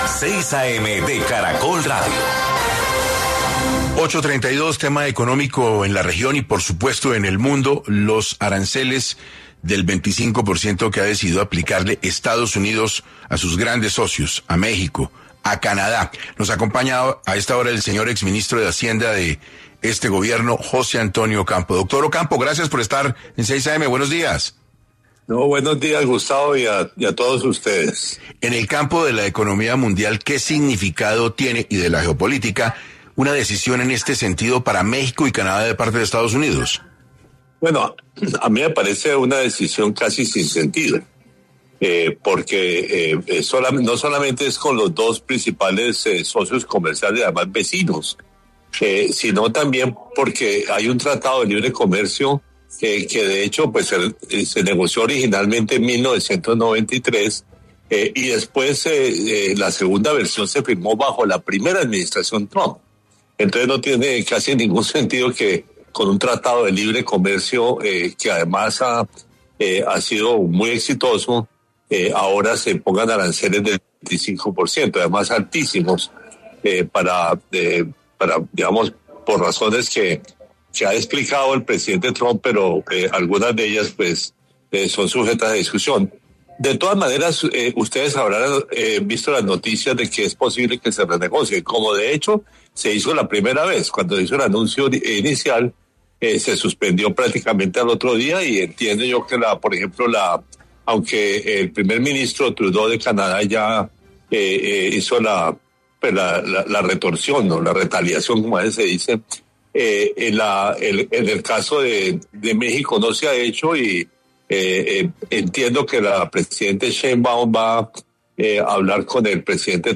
El exministro de Hacienda del gobierno de Gustavo Petro, José Antonio Ocampo, habló en 6AM de Caracol Radio sobre los más recientes aranceles del 25% impuestos por la administración de EEUU a Canadá y México.